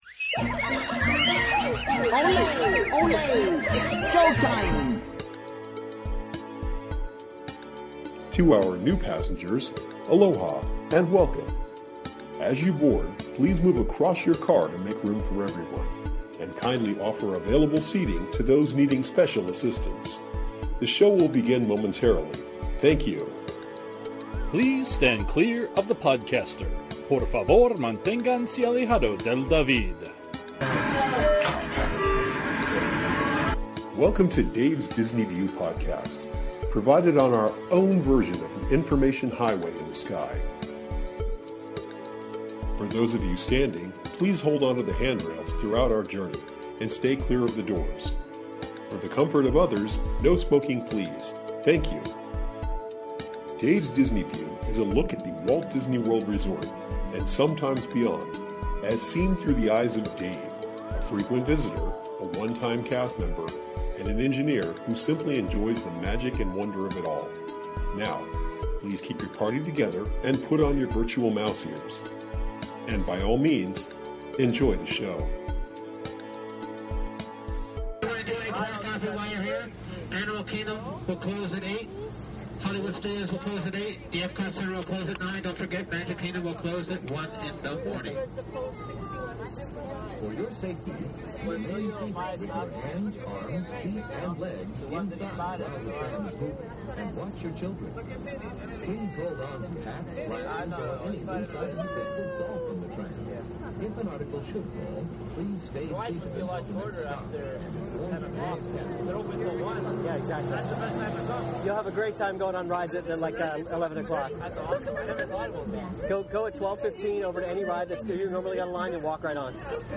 Follow me on my day in the Magic Kingdom. I ride the tram, head over to the pooh ride, check out peter pan, visit the monsters inc laugh floor, and of course give my thoughts about some topics.